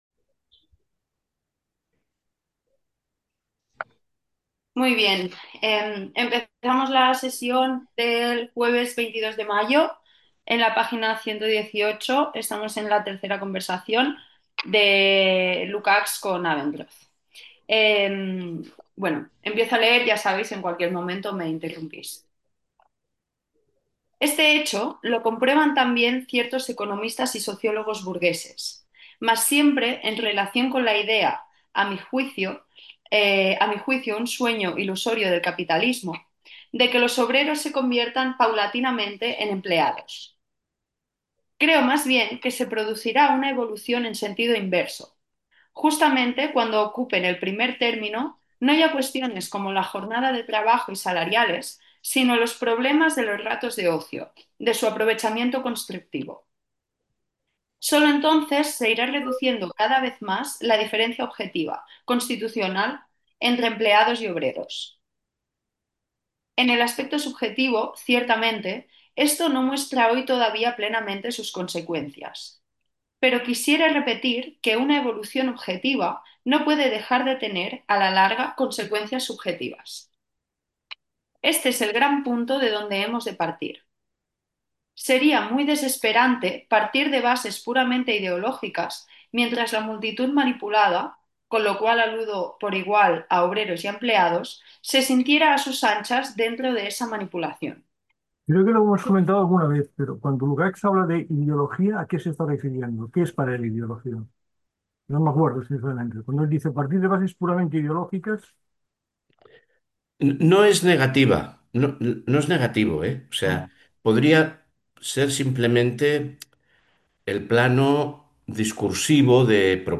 La forma de proceder es leer anticipadamente unas 20 o 25 páginas de texto, que posteriormente son releídas y comentadas en una puesta en común, que dirige un monitor.
En cualquier caso, la grabación del seminario será publicada posteriormente en la web de Espaimarx.